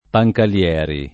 Pancalieri [ pa j kal L$ ri ] top. (Piem.)